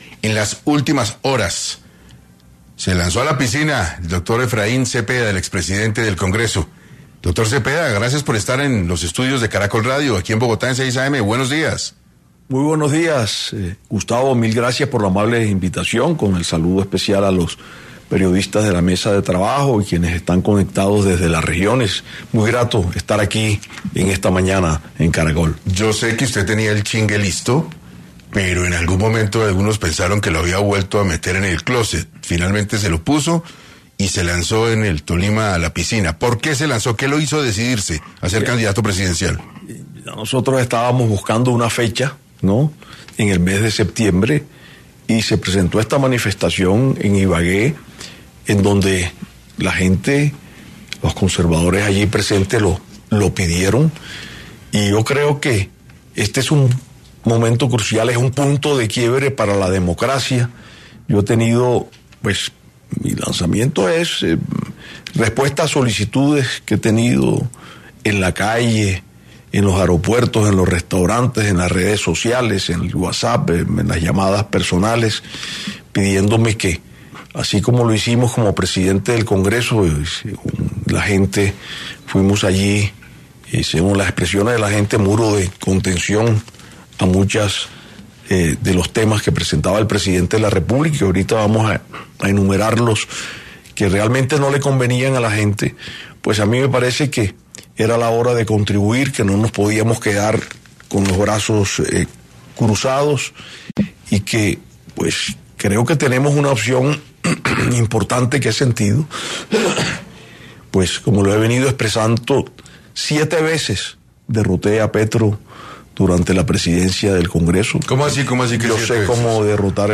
Efraín Cepeda, expresidente del Senado, senador por el partido Conservador y precandidato presidencial, pasó por 6AM para hablar de su enfoque para encarar la carrera por el cargo de jefe de Estado.